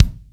R.L KICK.wav